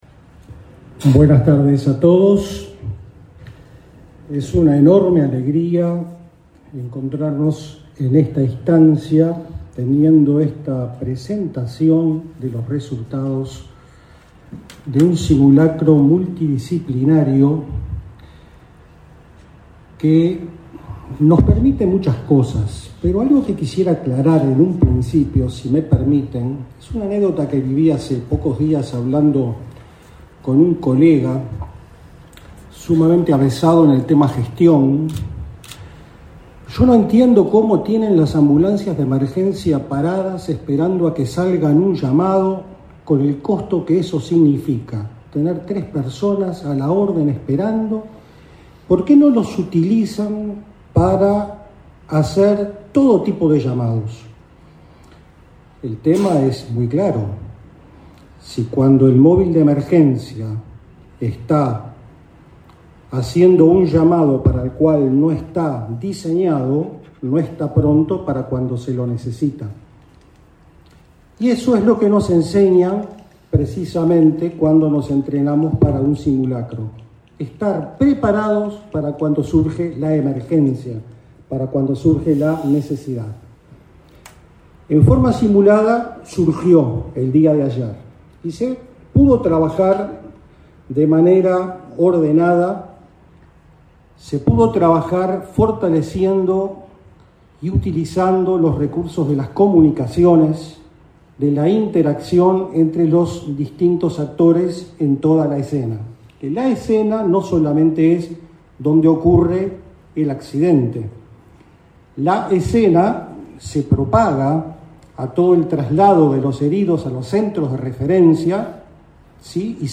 Conferencia de prensa de evaluación del simulacro organizado por ASSE y otras instituciones
La Administración de los Servicios de Salud del Estado (ASSE), junto a otras instituciones, realizó, este 25 de junio, una conferencia de prensa por la presentación de trabajo interinstitucional por gestión de riesgos. Participaron del evento el presidente de ASSE, Marcelo Sosa, y el director de SAME Uruguay, José Antonio Rodríguez.